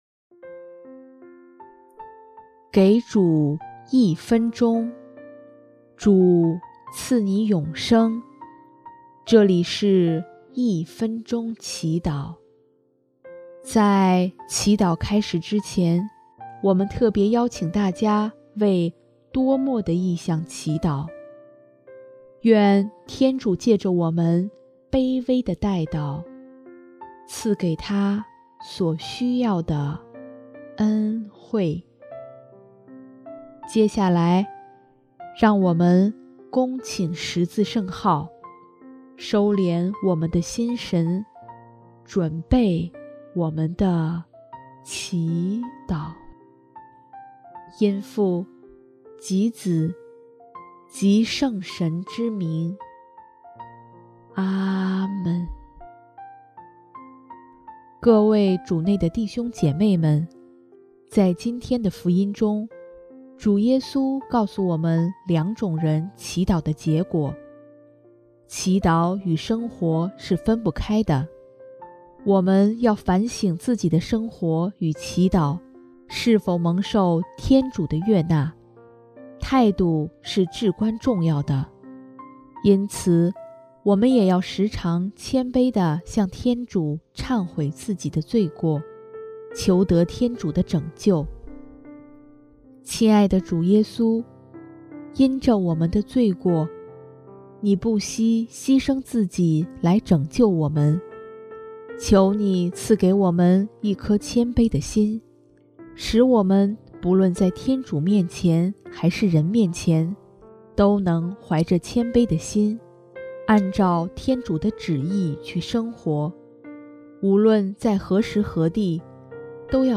音乐： 主日赞歌